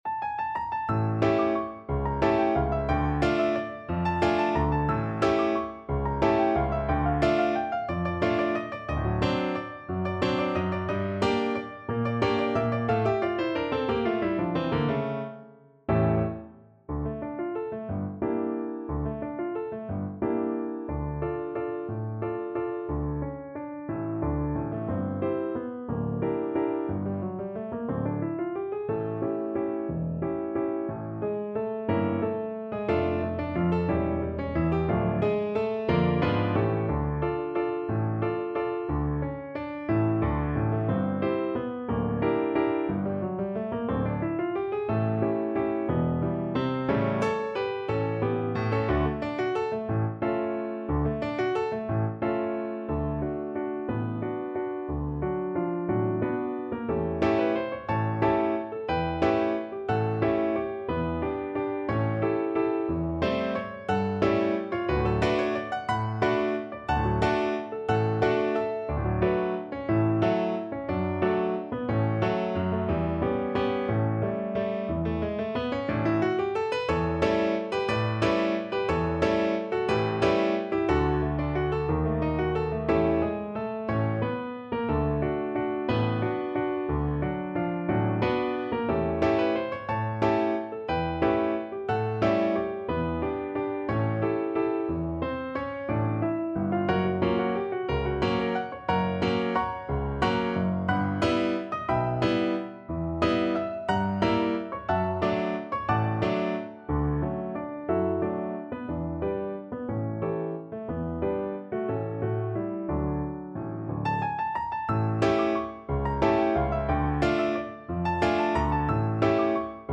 3/4 (View more 3/4 Music)
Allegro movido =180 (View more music marked Allegro)